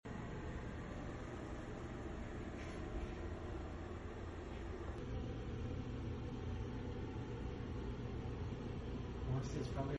Kitchen Fire At Stubborn Goat Sound Effects Free Download